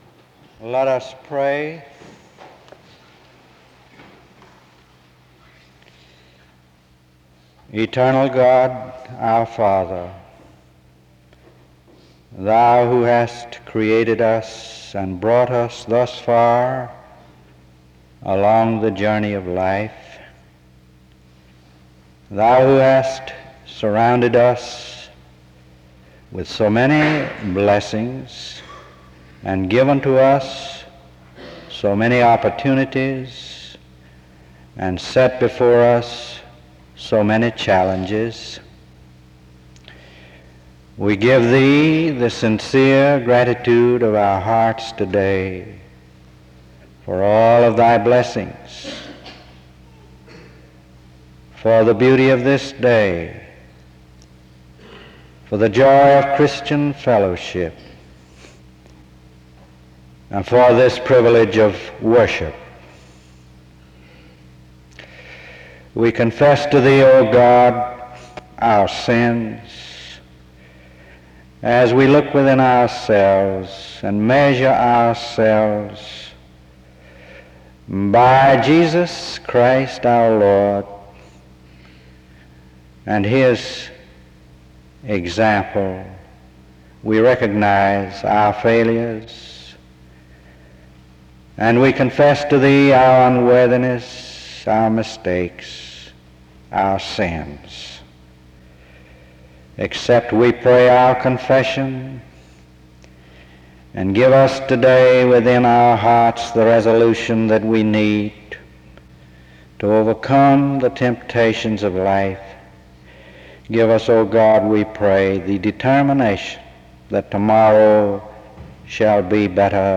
The service begins with an opening word of prayer from 0:00-3:33.
There is closing music from 23:44-24:27.